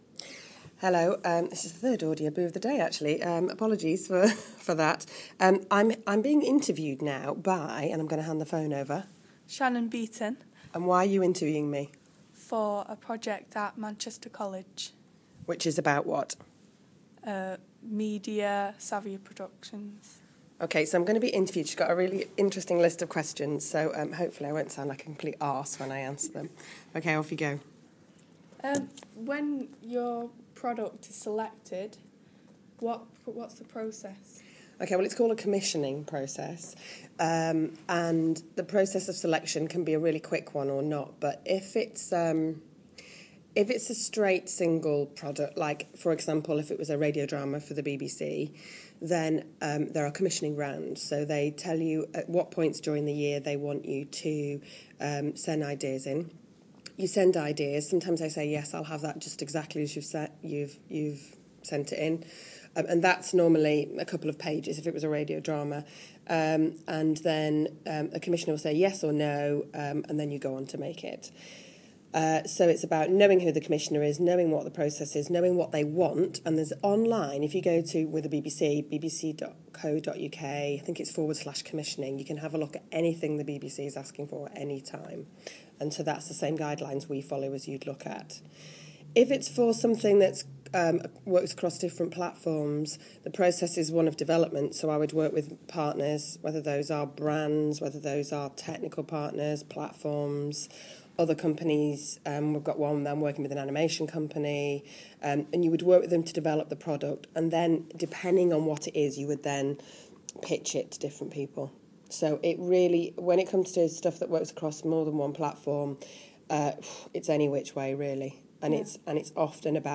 Being interviewed